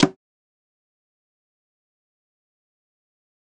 X Bitch_Rim.wav